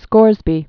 (skôrzbē)